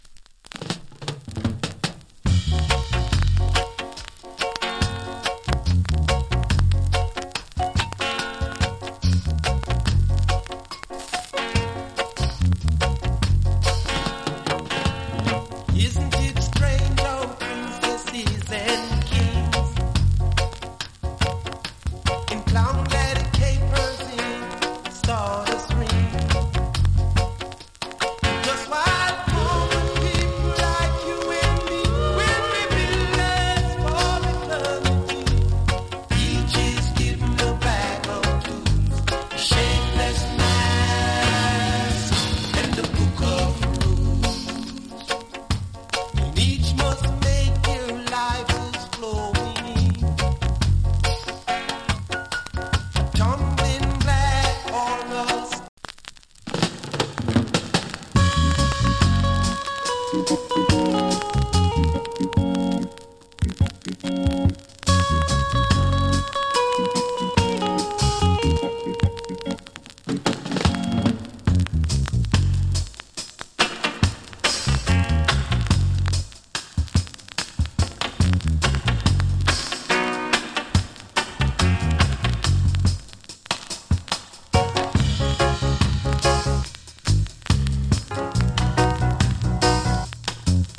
チリ、パチノイズ有り。